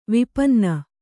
♪ vipanna